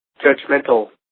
Ääntäminen
Ääntäminen US